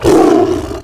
combat / creatures / tiger / he / hurt3.wav
hurt3.wav